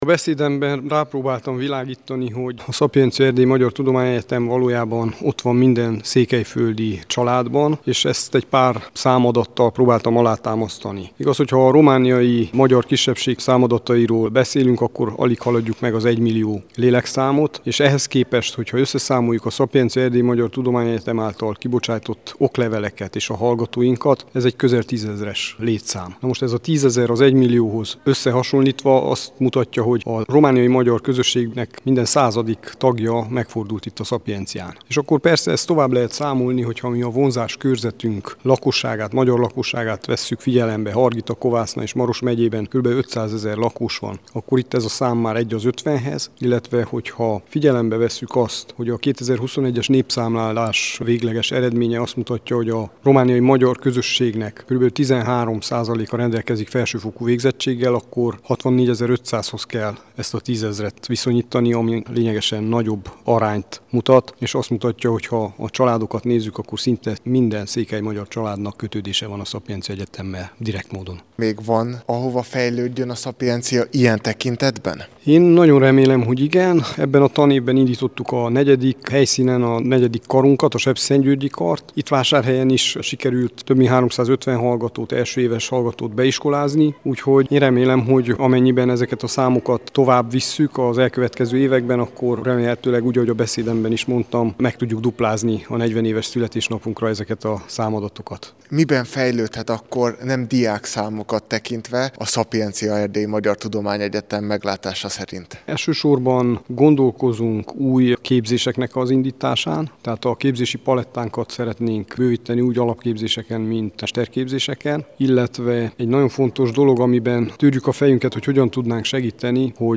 Az immáron hagyományosnak számító ünnepséget idén október 7-én, szombaton tartották.